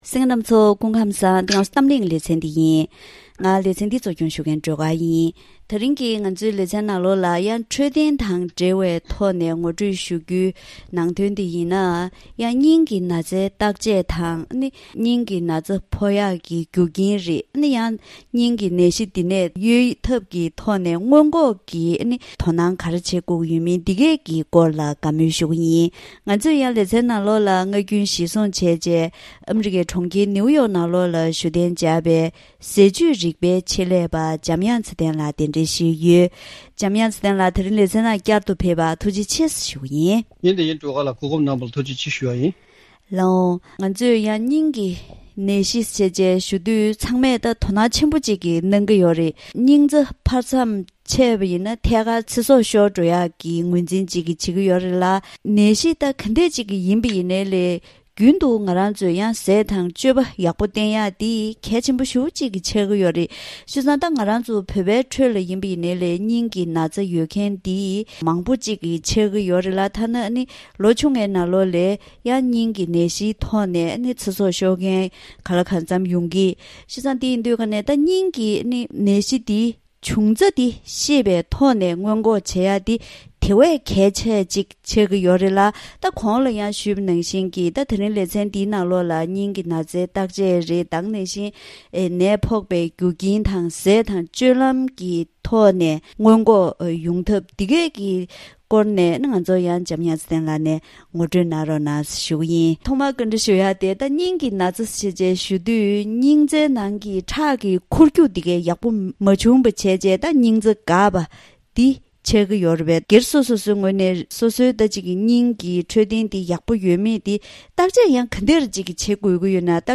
ད་རིང་གི་གཏམ་གླེང་ལེ་ཚན་ནང་སྙིང་གི་ན་ཚའི་བརྟག་དཔྱད་དང་ནད་ཕོག་པའི་རྒྱུ་རྐྱེན། ནད་རྟགས། སྔོན་འགོག་སོགས་ངོ་སྤྲོད་ཞུས་པའི་ཐོག་ནས་རྒྱུན་དུ་དོ་སྣང་བྱེད་སྟངས་དང་ཟས་སྤྱོད་ལ་བསྟེན་སྟངས་དང་བཅས་པ་ངོ་སྤྲོད་ཞུས་པ་ཞིག་གསན་རོགས་གནང་།